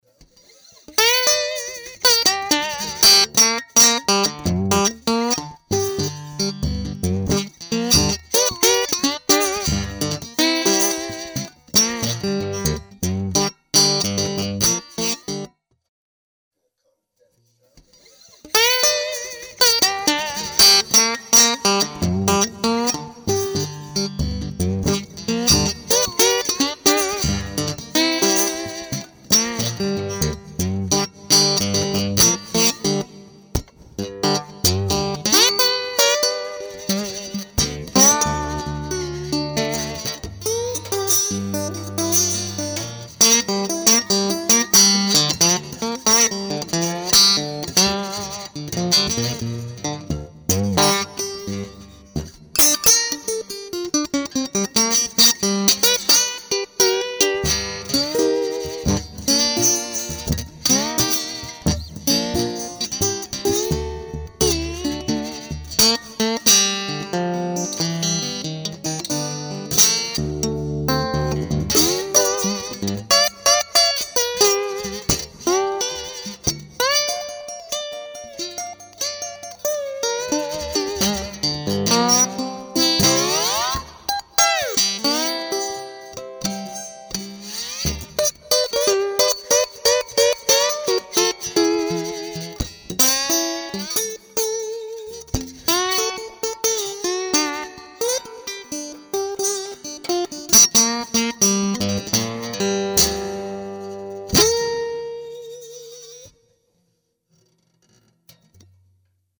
The following audio clips used an indentical basic setup to show the capabilities and quality that can be obtained by using the AMP+.
The guitar was plugged straight into the Super 57 or M59 AMP+ and then routed into a Focusrite Saffire 6 USB Interface.
Some of the clips have a dry section 1st and then with added post production reverb to show what a typical studio track might sound like.
Amistar Stager Resonator